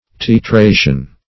Titration \Ti*tra"tion\, n. (Chem.)